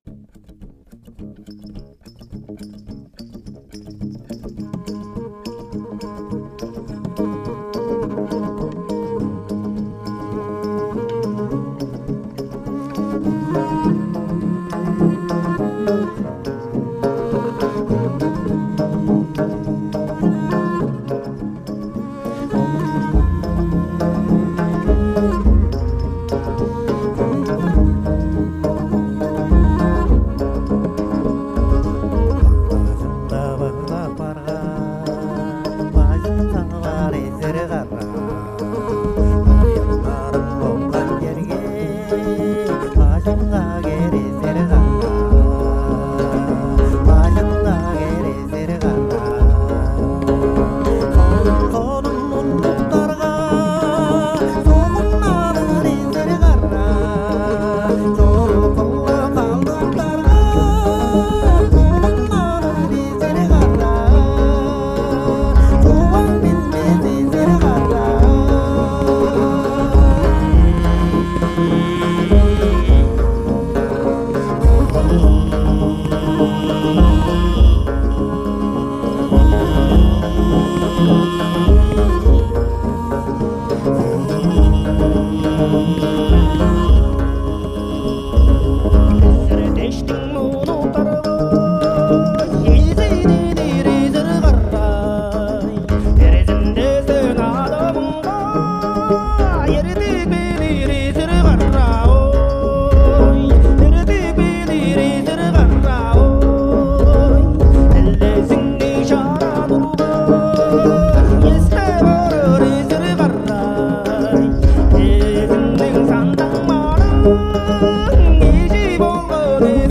Впрочем сразу предупреждаю музыка весьма специфичная.